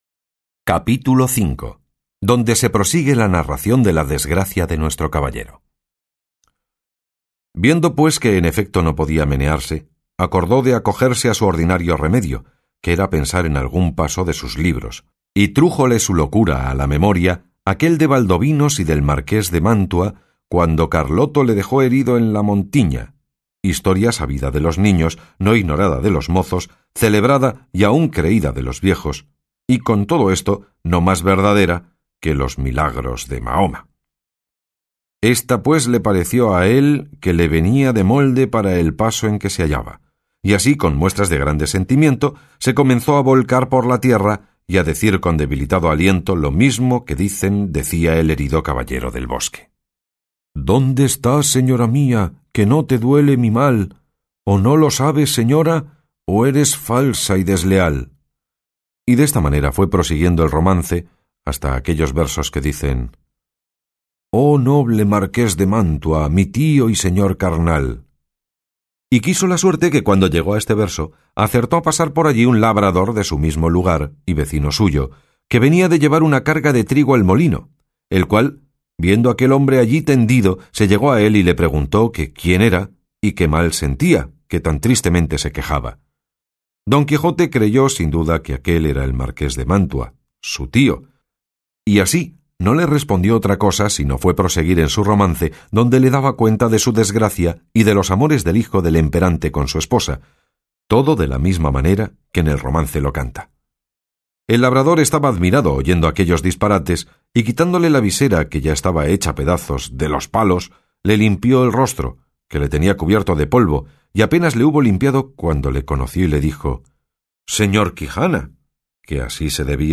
Audio Books 2 – Don Quijote De La Mancha P1